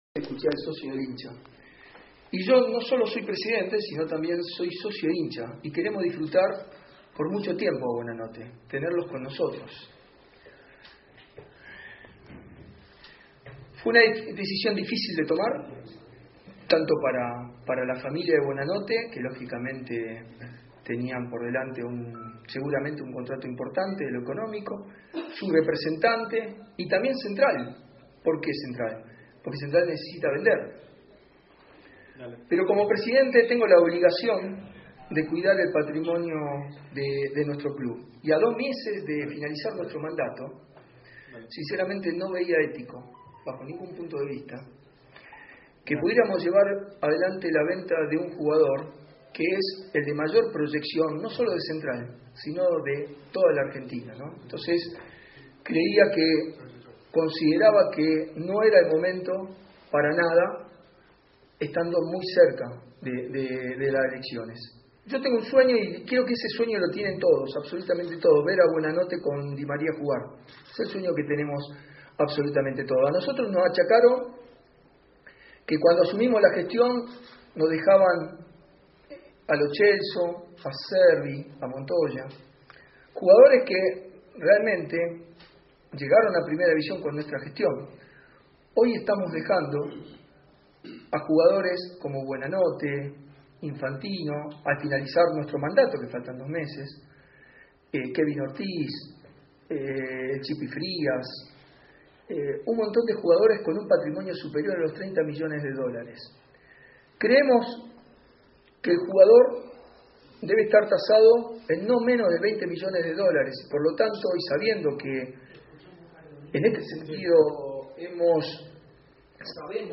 En conferencia de prensa, realizada en el Gigante de Arroyito